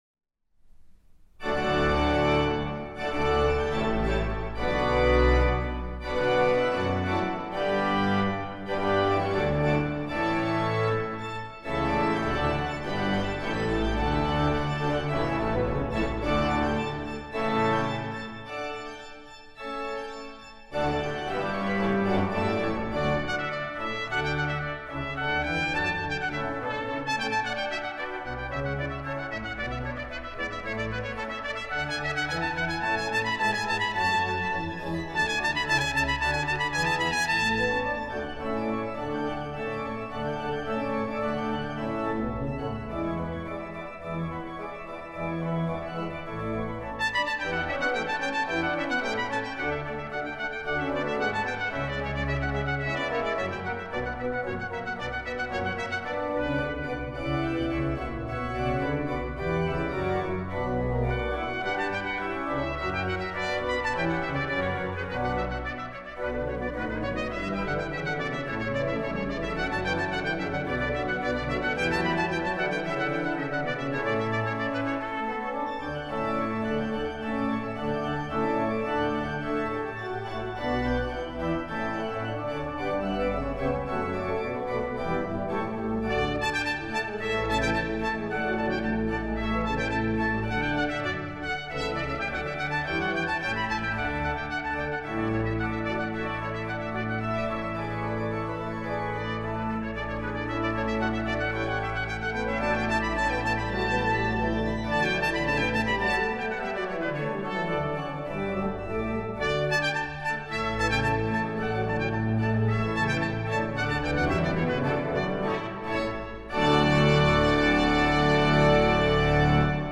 Organ Festival at London's Southbank Centre
trumpet
organ